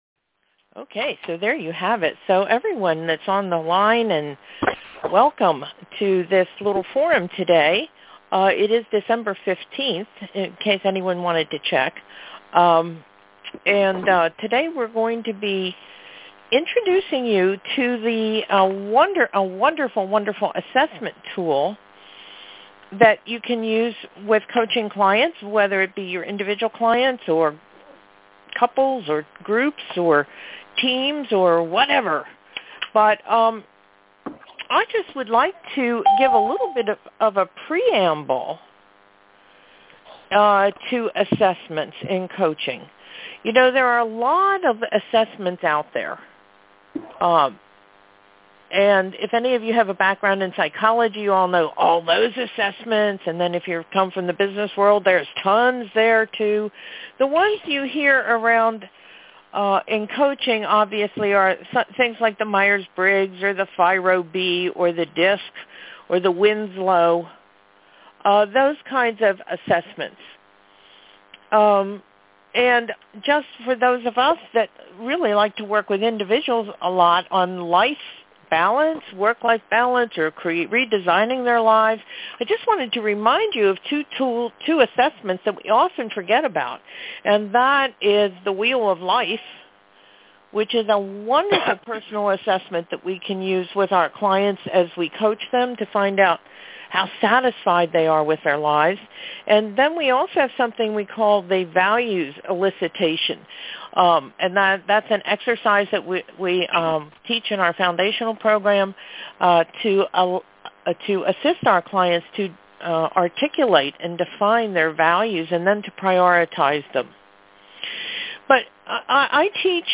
hosts a panel discussion to hear why many of our faculty members are passionate about using the PeopleMap assessment in their coaching practice.